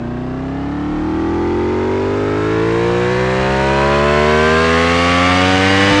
f1_03_accel.wav